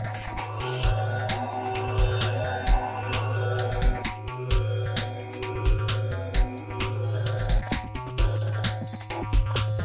描述：Sound made with Regulator Mod in ReBirth.
标签： beat rebirth regulator reso
声道立体声